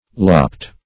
Lopped - definition of Lopped - synonyms, pronunciation, spelling from Free Dictionary
Lop \Lop\ (l[o^]p), v. t. [imp. & p. p. Lopped; p. pr. & vb.